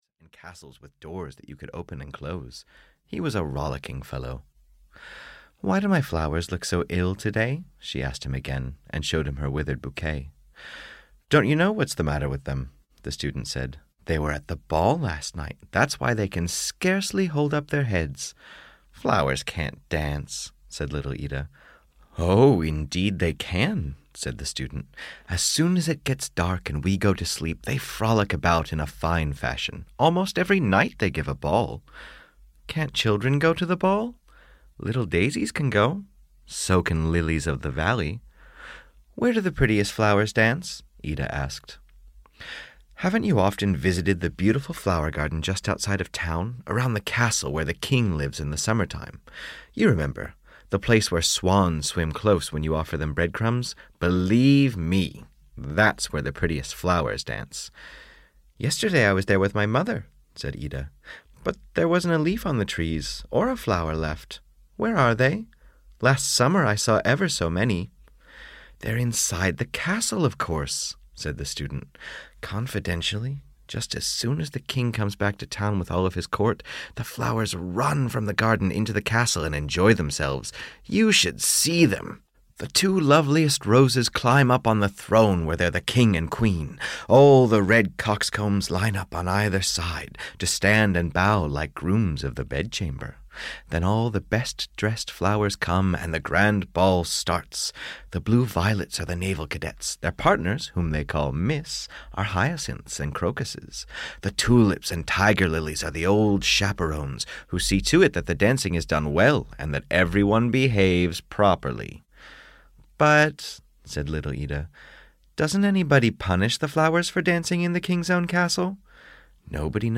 Little Ida's Flowers (EN) audiokniha
Ukázka z knihy